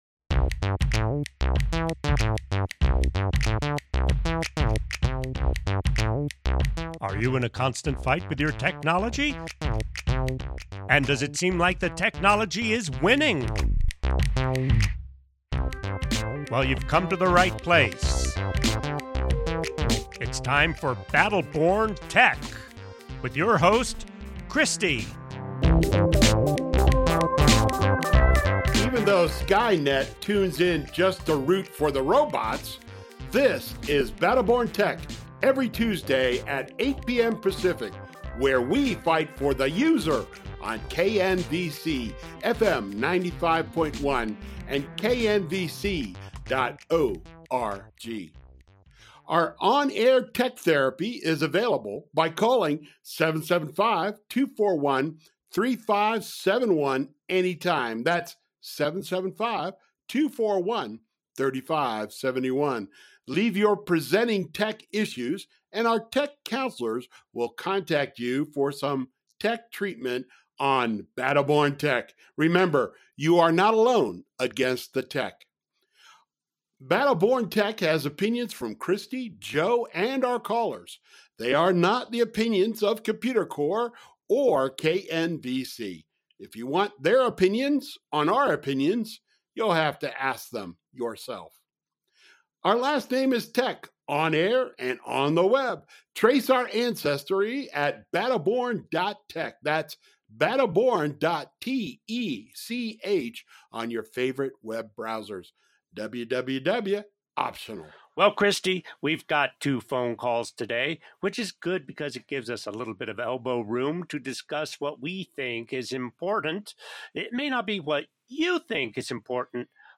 BBT from 2020 Has High Quality Stereo Recordings